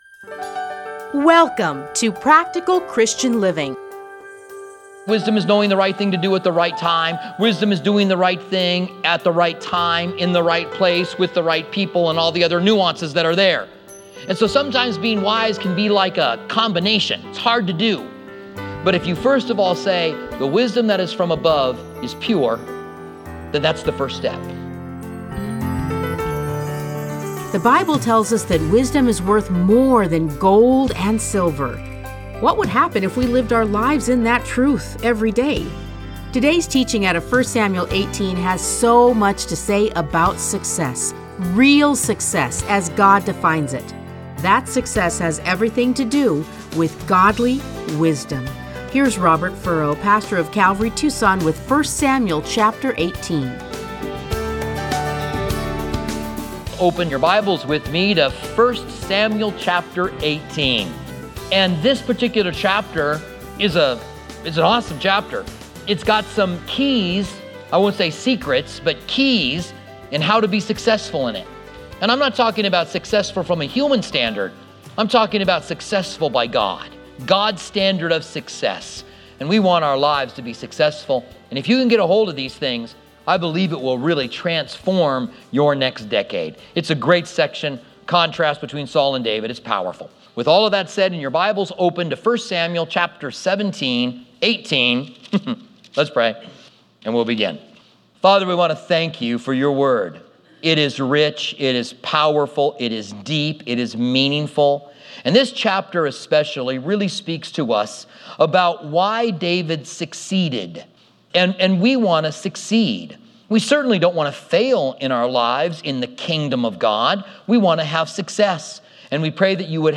Listen to a teaching from 1 Samuel 18:1-30.